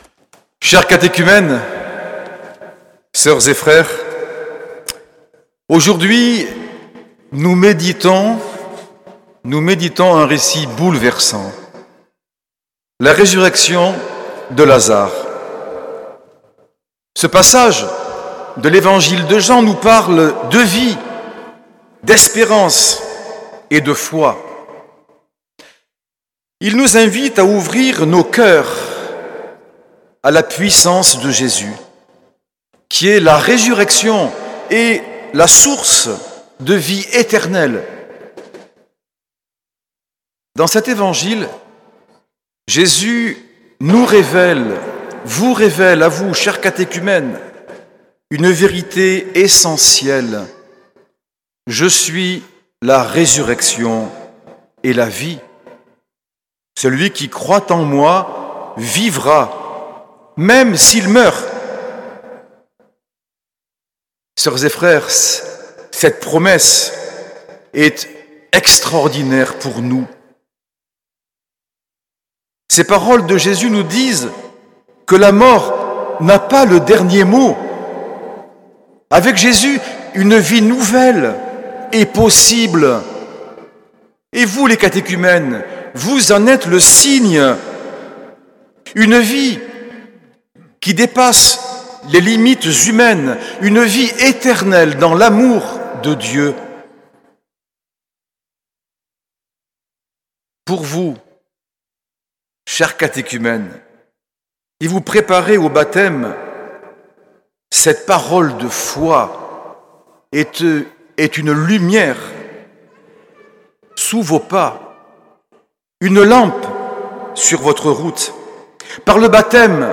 Homélie de Monseigneur Norbert TURINI, dimanche 6 avril 2025, messe du troisième scrutin des catéchumènes
L’enregistrement retransmet l’homélie de Monseigneur Norbert TURINI, suivi de son adresse personnelle à l’assemblée.